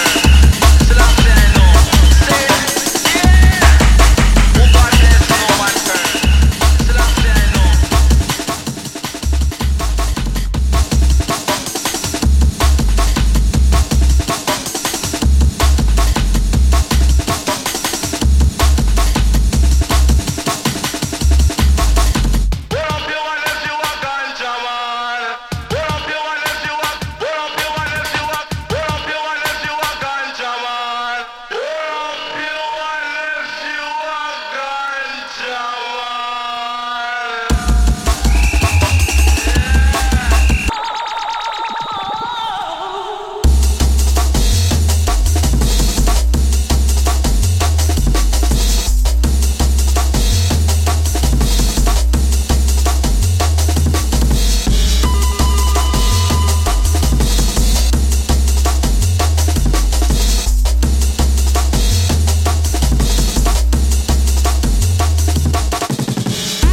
White Label Test Pressing